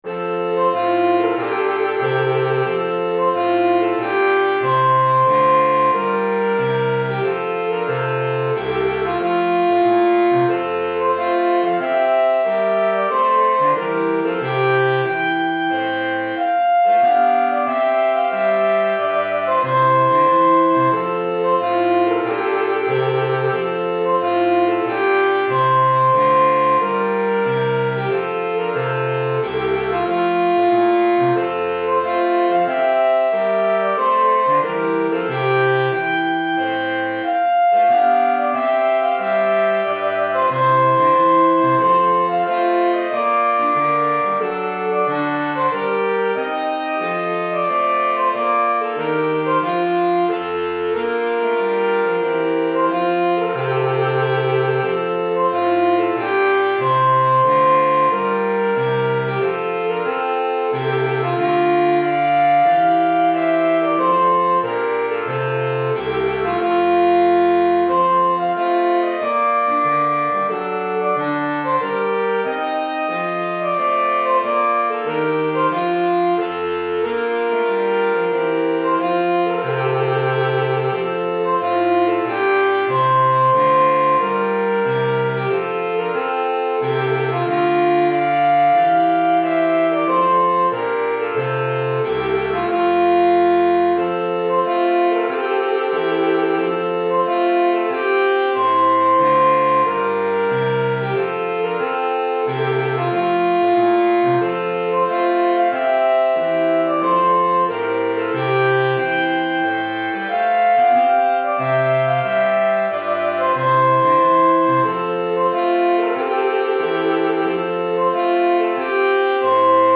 Saxophone Soprano, Saxophone Alto, Saxophone Ténor